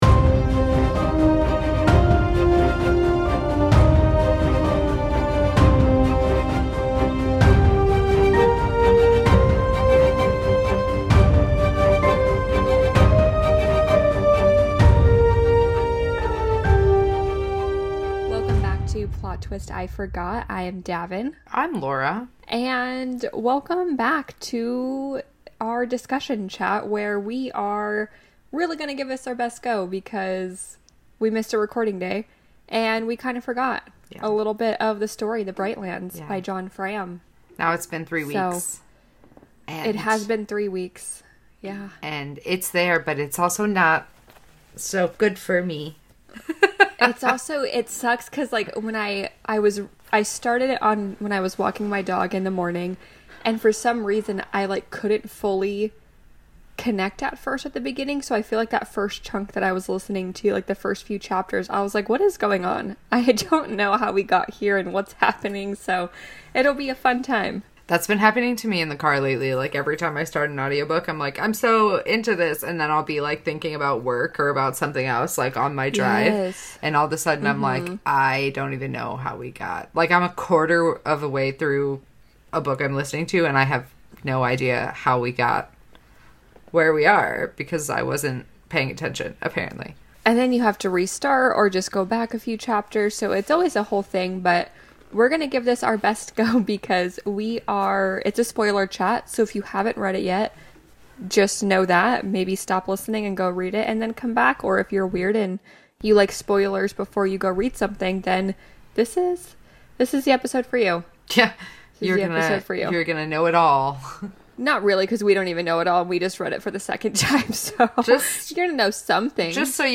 Today we’re talking all things The Bright Lands by John Fram. And by talking, we mean shambling through a spoiler chat.
Buckle up, get your southern accents ready, and pay attention because we apparently weren’t.